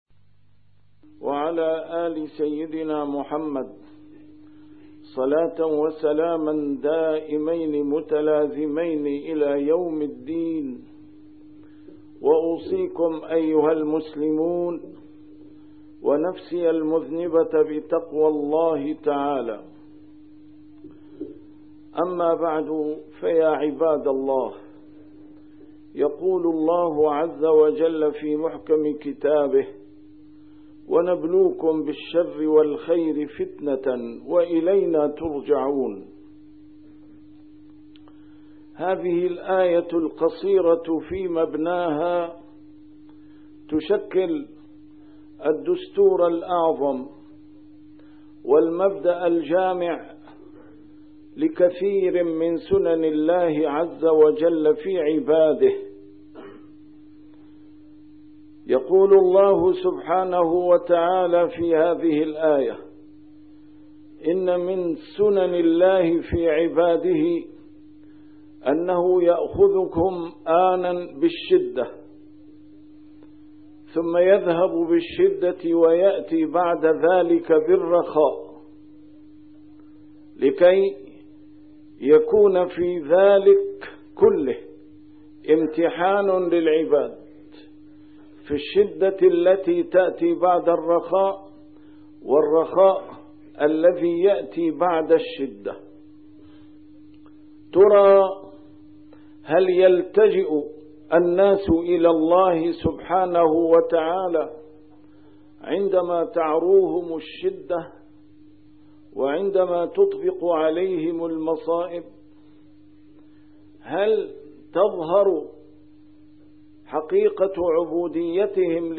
A MARTYR SCHOLAR: IMAM MUHAMMAD SAEED RAMADAN AL-BOUTI - الخطب - ونبلوكم بالخير والشر فتنة